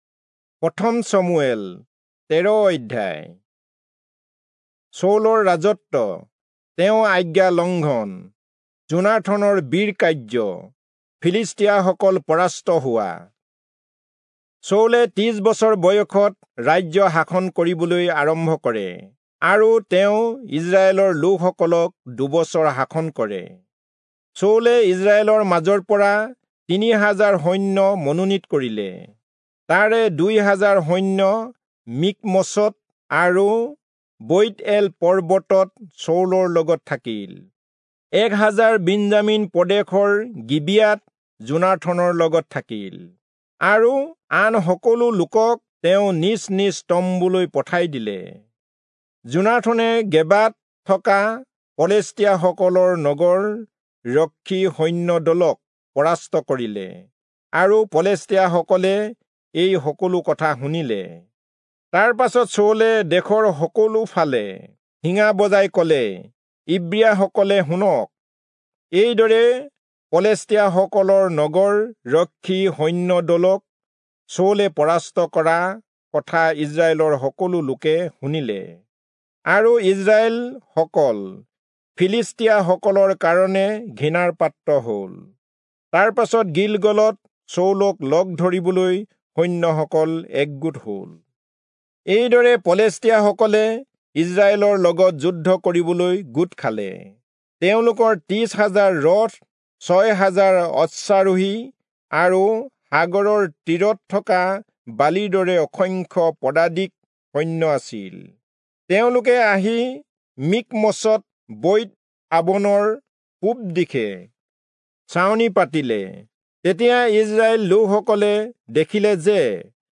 Assamese Audio Bible - 1-Samuel 29 in Gntwhrp bible version